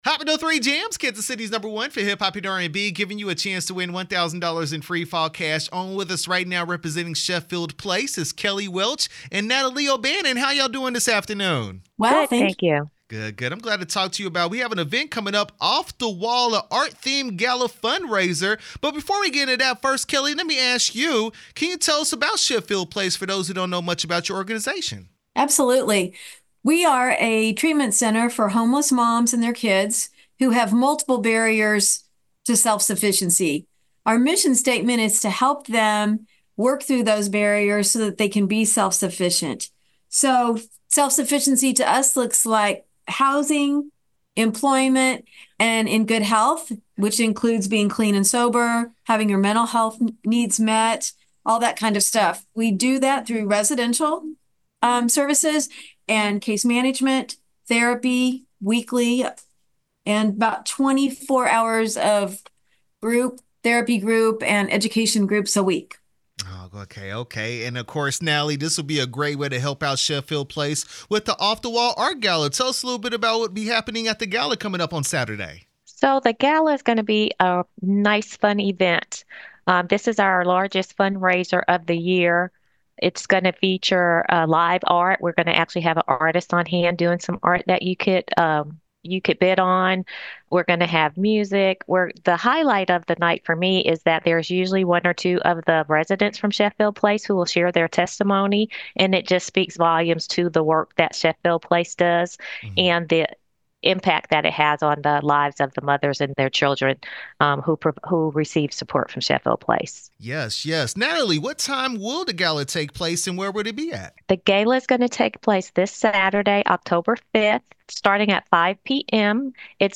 Sheffield Place Off The Wall Art Gala interview 10/3/24